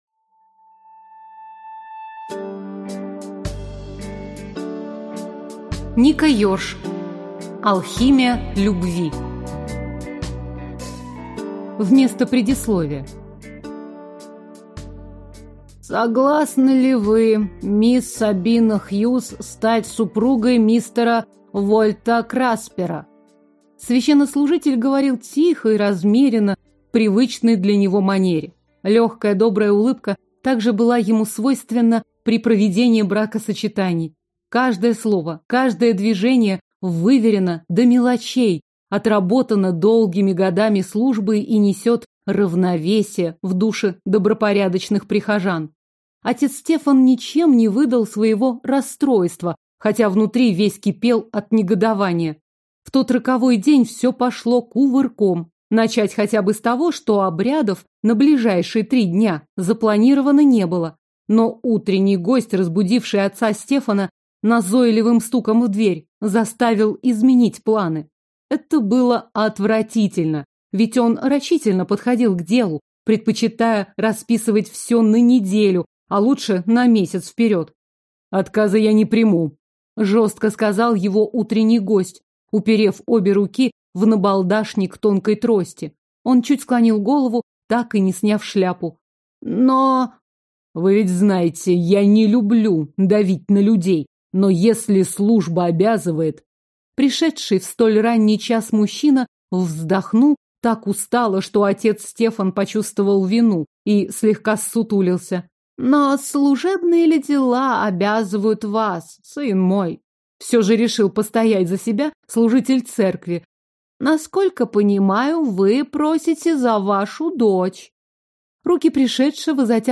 Аудиокнига Алхимия любви | Библиотека аудиокниг